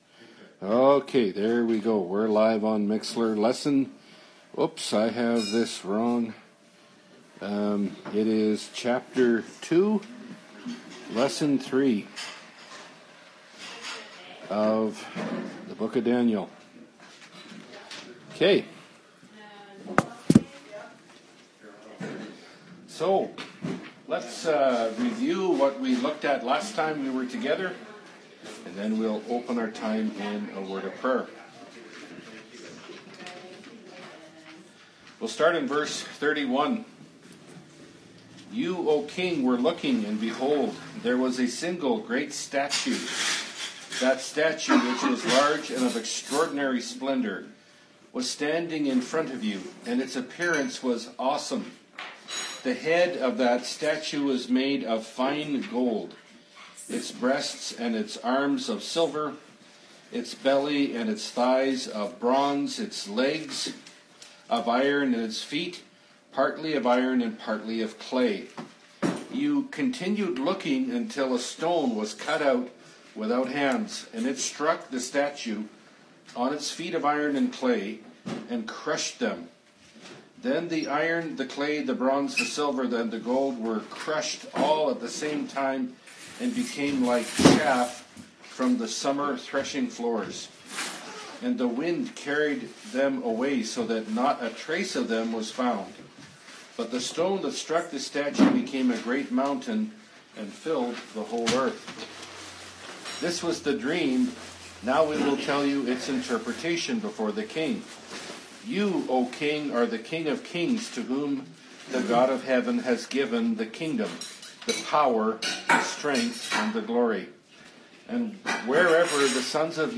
Bible Study – Daniel 2 – Part 2 (2017)
Daniel Category: Bible Studies Key Passage: Daniel 2 Download this Audio File